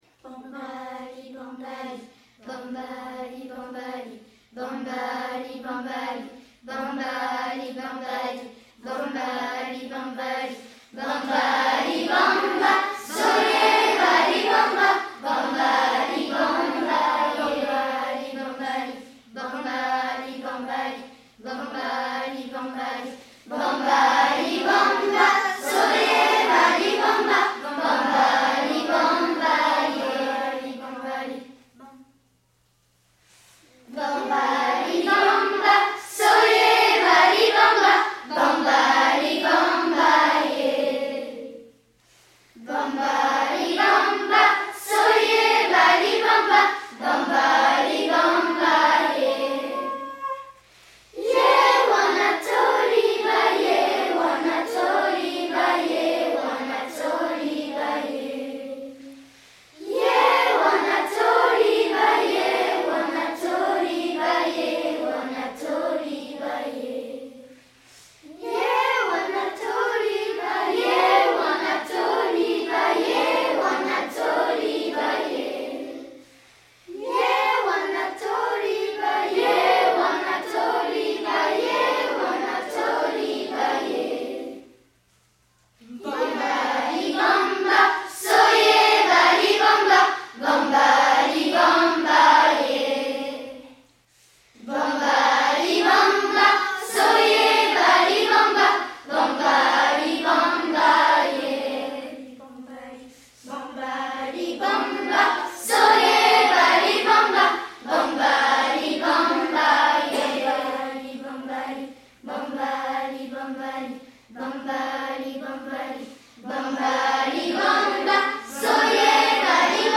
jolies voix de nos CM1 et CM2.